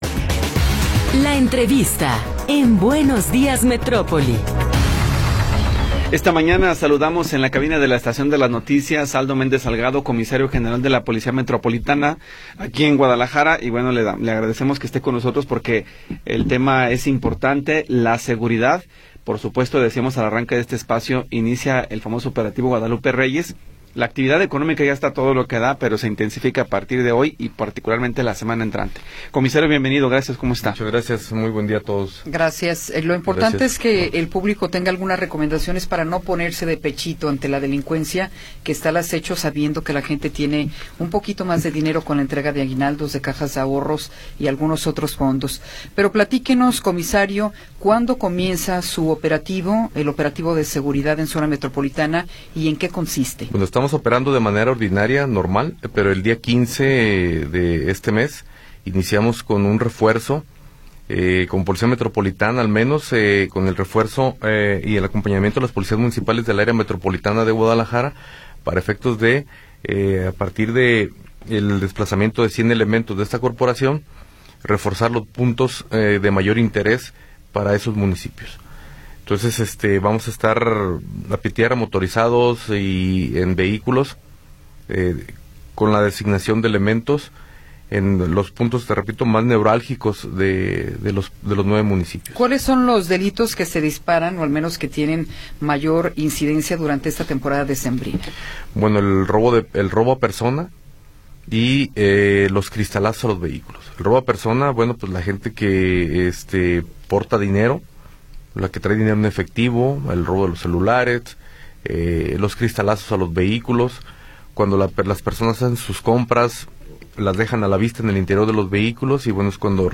Entrevista con Aldo Méndez Salgado